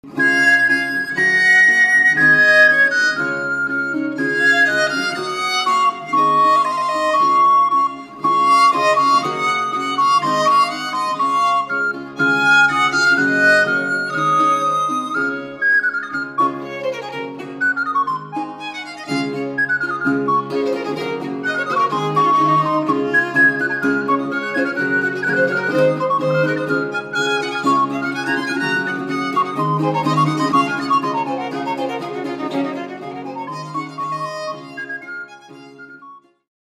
Early music